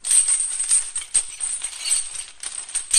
Door Creak
Door Creak is a free foley sound effect available for download in MP3 format.
381_door_creak.mp3